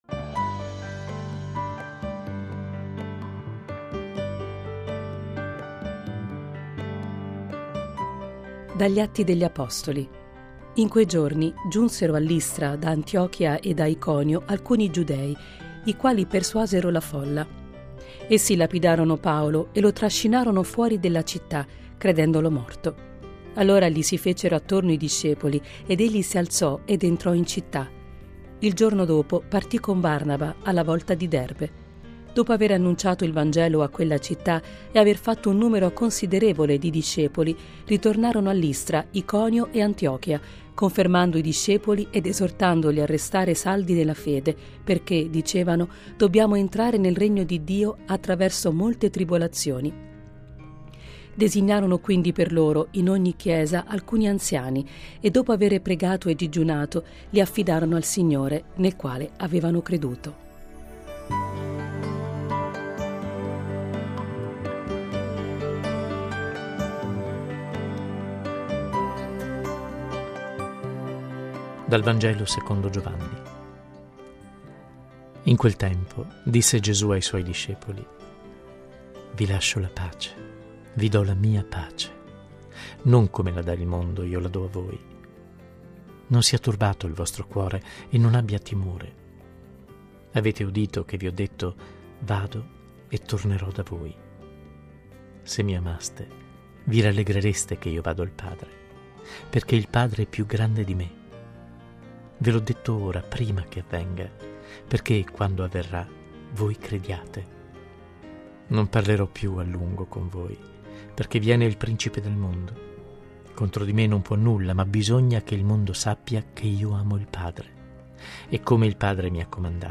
Le letture del giorno (prima e Vangelo) e le parole di Papa Francesco da VaticanNews: